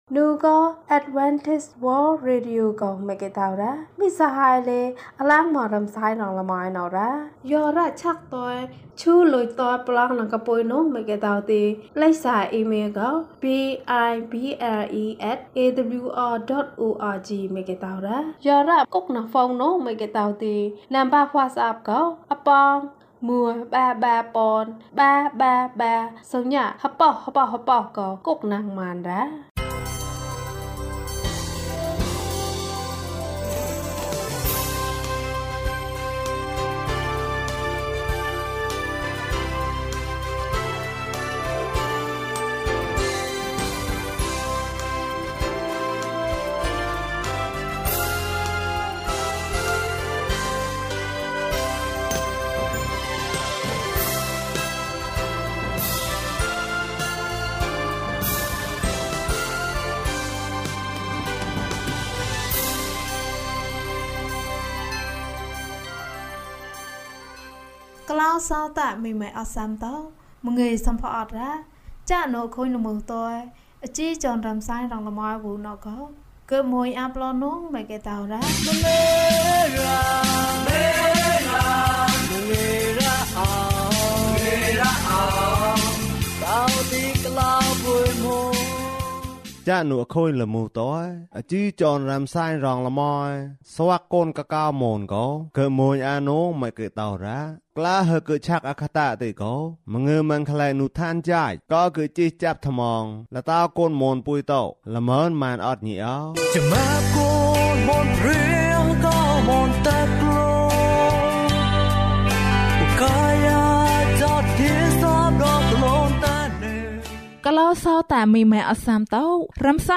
ယေရှုနှင့်အတူအသက်တာ။၀၁ ကျန်းမာခြင်းအကြောင်းအရာ။ ဓမ္မသီချင်း။ တရားဒေသနာ။